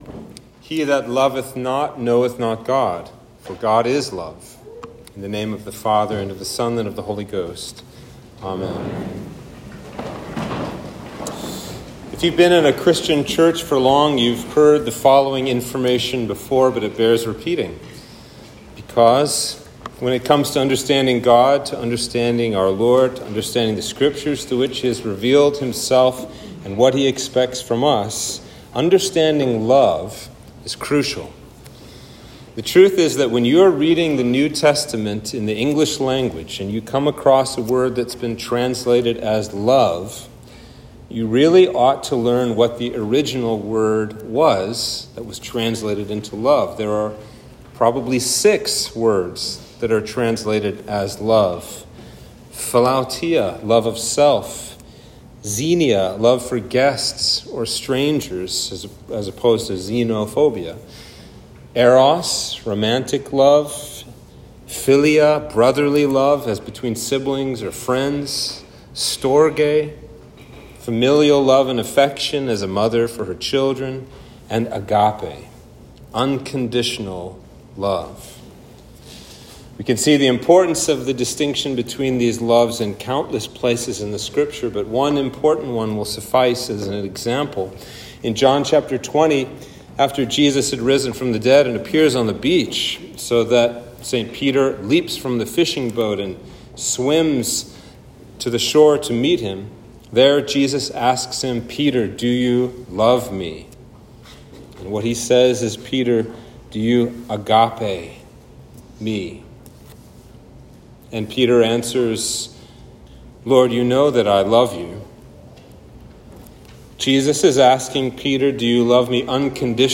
Sermon for Trinity 1